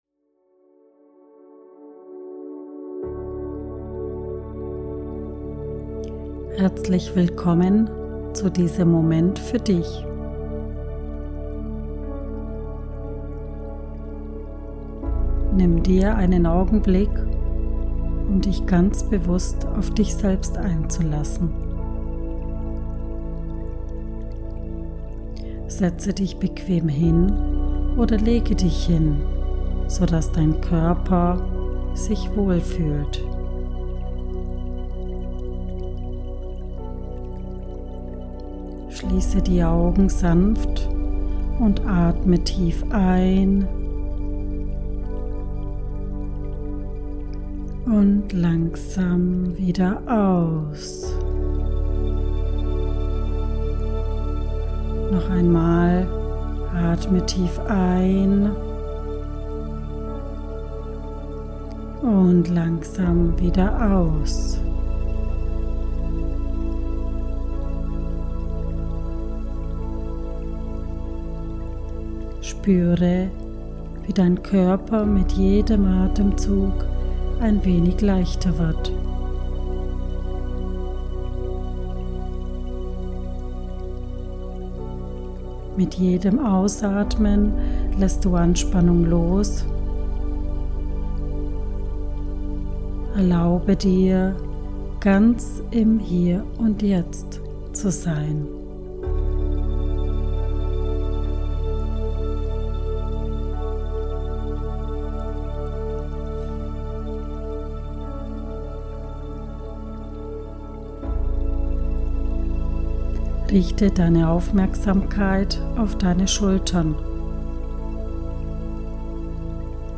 Meditation Innere Stärke
meditation-innere_staerke_aktivieren.mp3